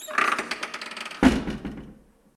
Abrir la puerta de un armario de cocina de madera
Sonidos: Acciones humanas
Sonidos: Hogar